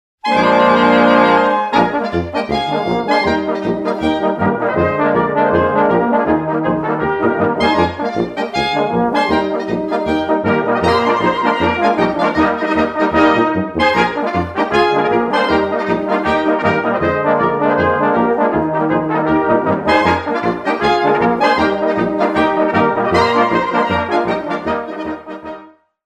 Ein wahrhaft bunter Reigen mit überlieferter und für den heutigen Gebrauch neugestalteter dörflicher Blasmusik!
Doerfliche_Blasmusik_99er_Galopp_01.mp3